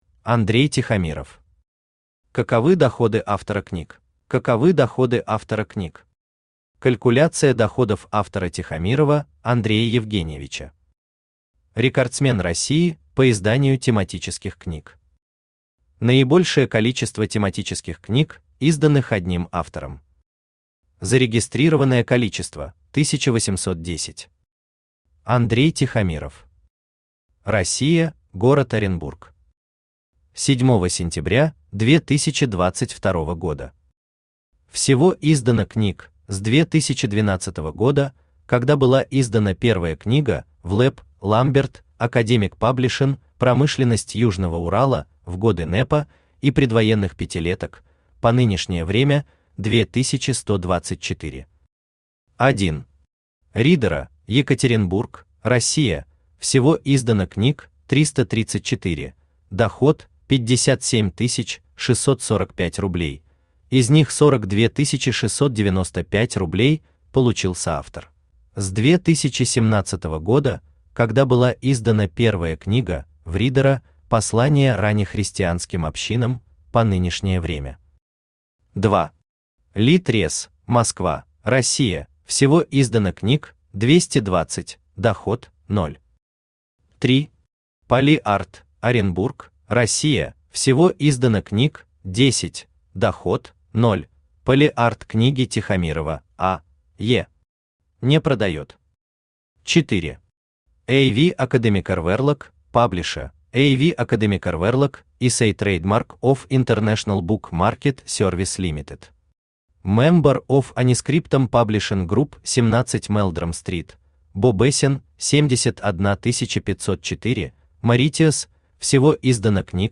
Аудиокнига Каковы доходы автора книг?